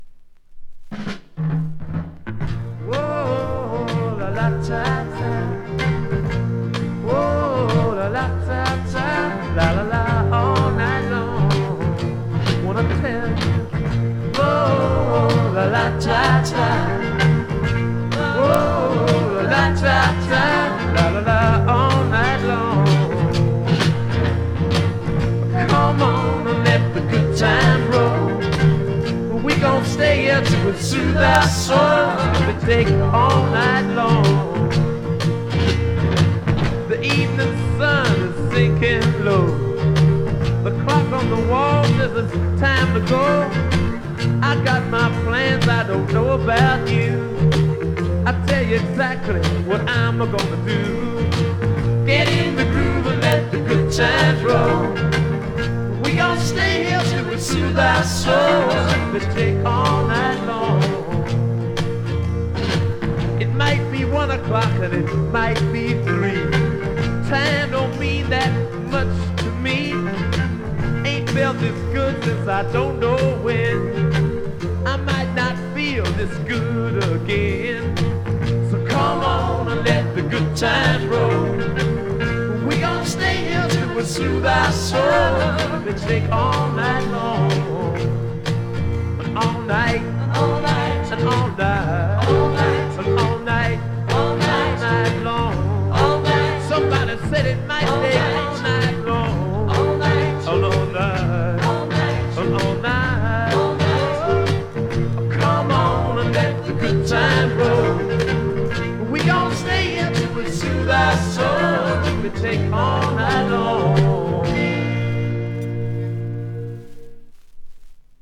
It's in stereo...sorta?